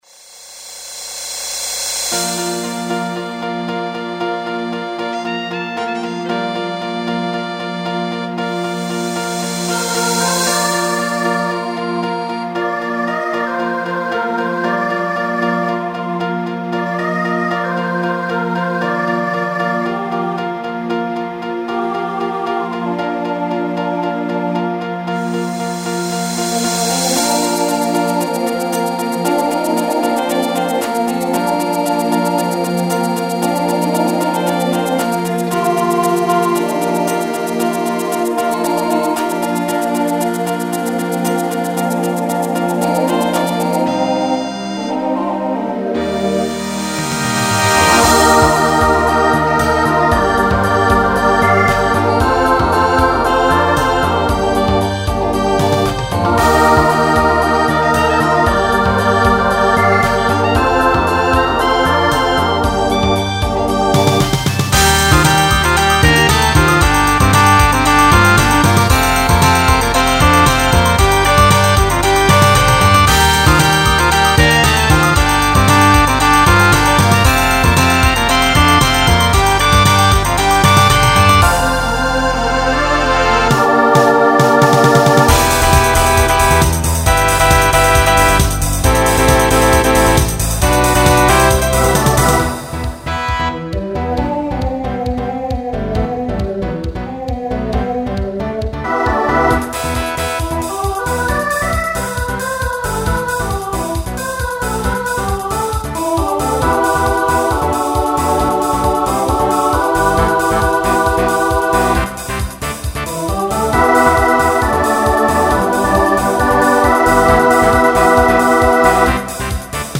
Genre Pop/Dance Instrumental combo
Opener Voicing SATB